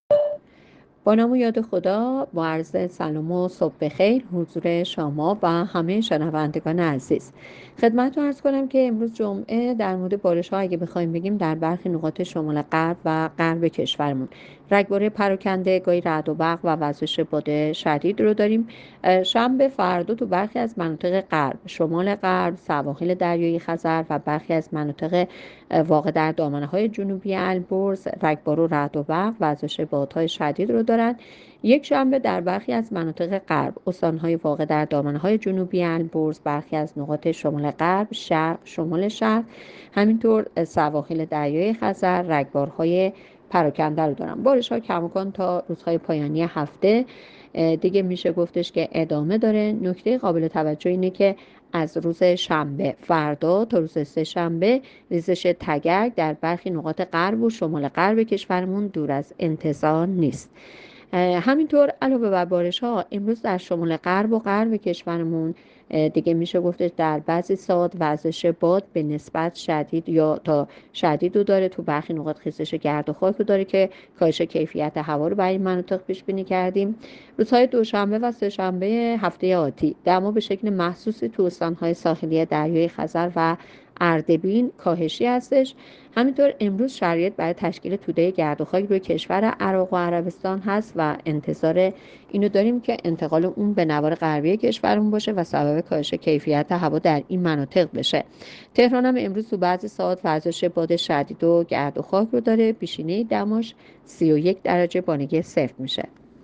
گزارش رادیو اینترنتی پایگاه‌ خبری از آخرین وضعیت آب‌وهوای چهارم اردیبهشت؛